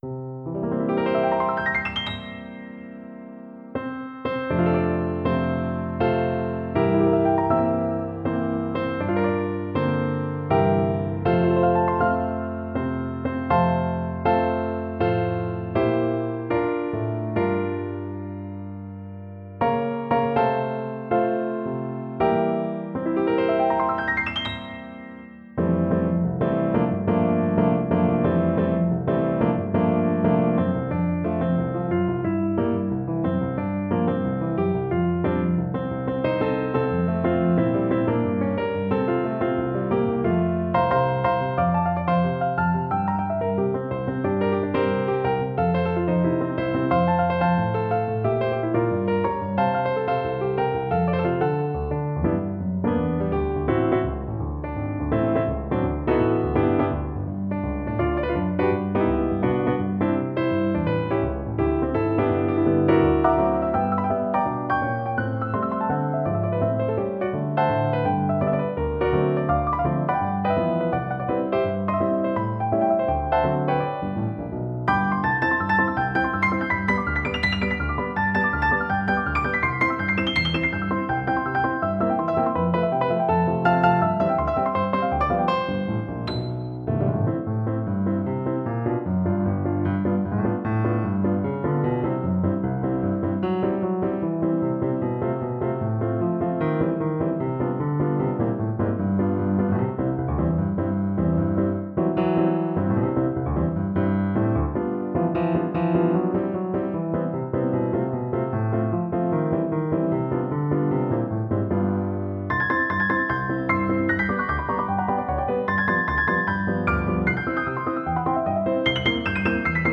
Nun in verschiedenen Stilistiken (du wirst es kaum wieder erkennen)